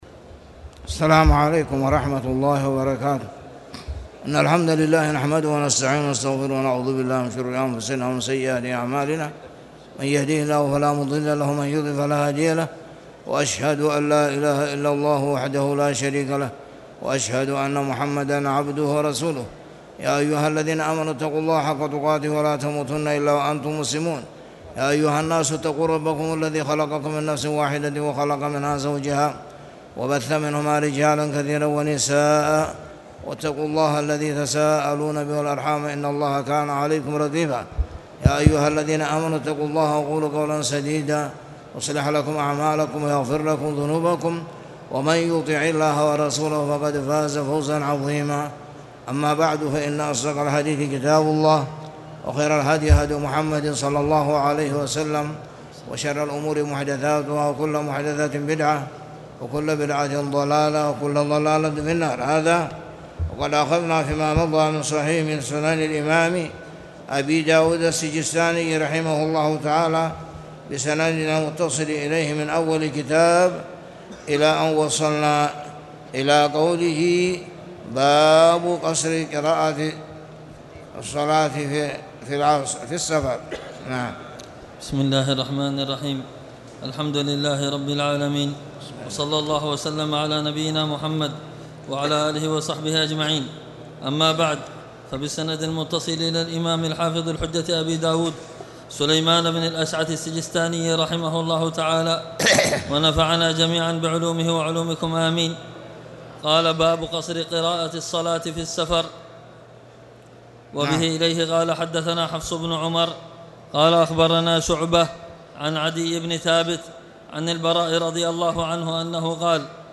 صلاة الخسوف
تاريخ النشر ١٠ جمادى الأولى ١٤٣٨ هـ المكان: المسجد الحرام الشيخ